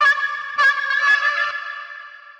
描述：Space harp
标签： 100 bpm Dance Loops Harmonica Loops 413.48 KB wav Key : Unknown
声道立体声